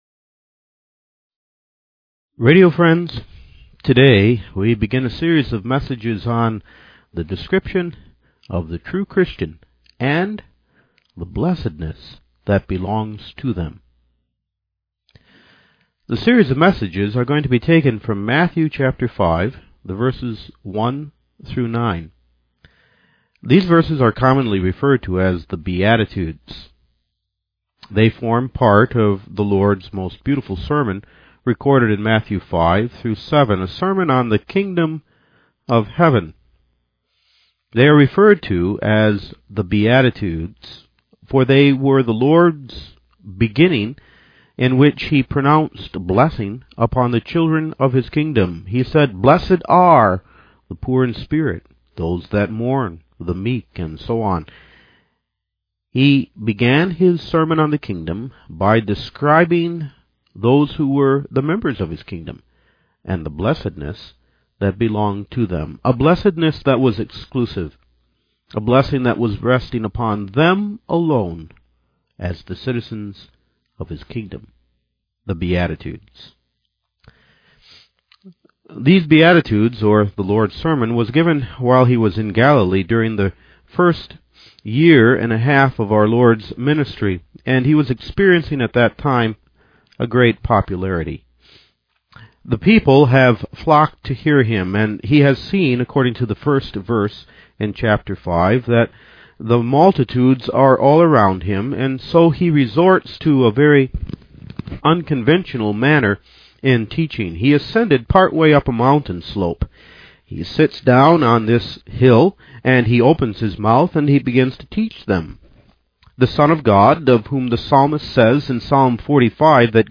Sermon on the Mount - Beatitudes